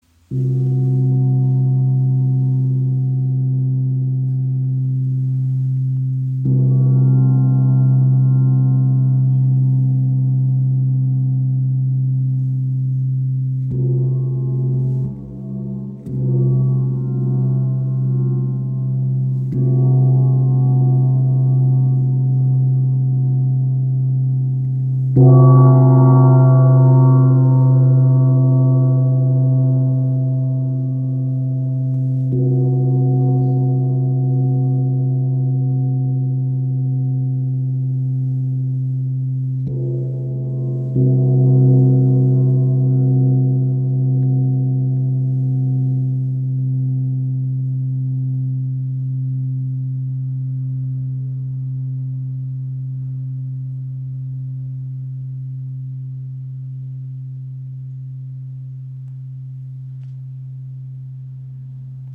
Handgefertigt von WOM Gongs in Italien – kraftvoller, klarer Klang.
Klangbeispiel
Sein voller Grundton und feine Obertöne erschaffen ein lebendiges Klangfeld, das Körper und Seele berührt.
Nur 2.3 kg – ideal für unterwegs und handgeführtes Spiel Kraftvoller, ausgewogener Klang mit feiner Obertonstruktur Edelstahl – langlebig, resonant und klangstark Dieser handgefertigte Edelstahl-Gong von WOM vereint Leichtigkeit mit Tiefe.